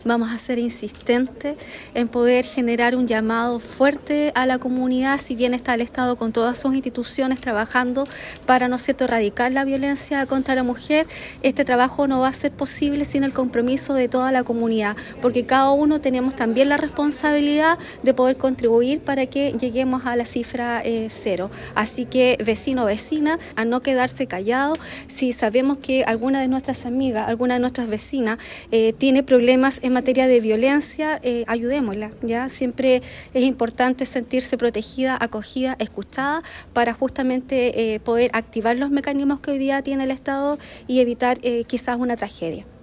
La actividad se realizó en la Sala de Sesiones del Municipio y contó con la participación de autoridades regionales, provinciales y locales; además de dirigentas sociales.
Del mismo modo, la Delegada Presidencial Provincial Claudia Pailalef, realizó un llamado a la comunidad para sumarse a esta campaña y no guardar silencio frente a un acto de violencia de género.